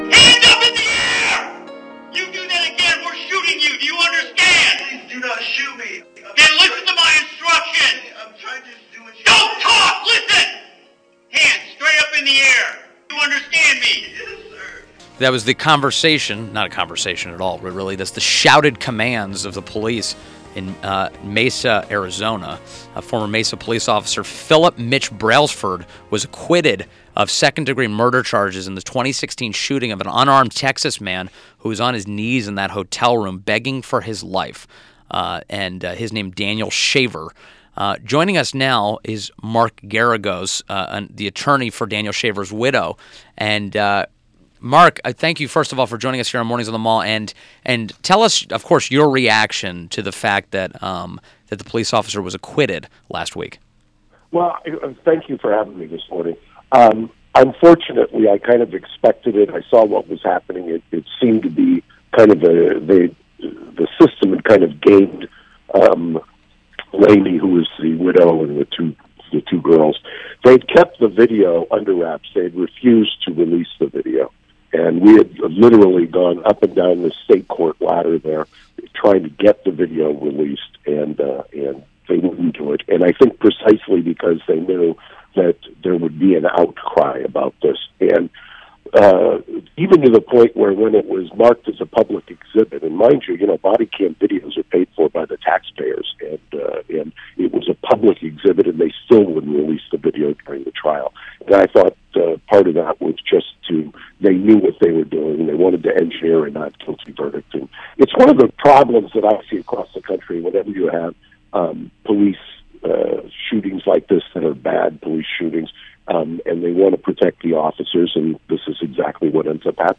WMAL Interview - MARK GERAGOS - 12.11.17